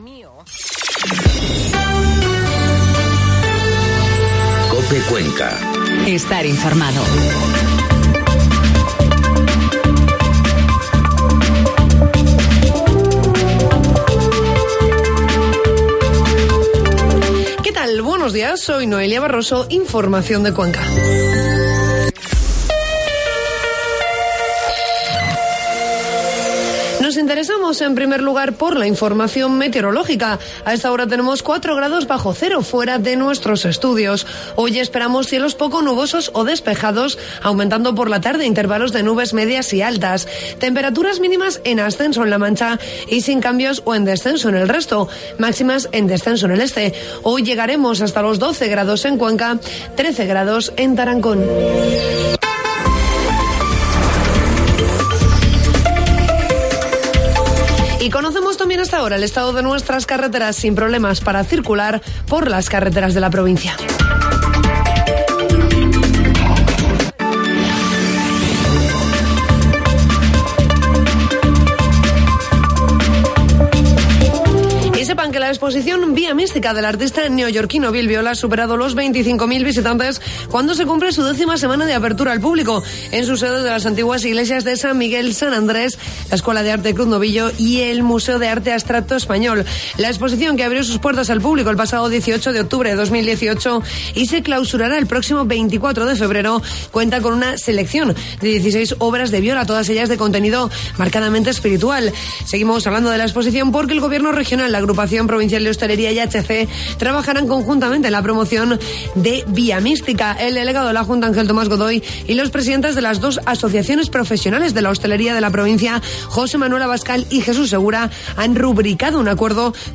Informativo matinal COPE Cuenca 3 de enero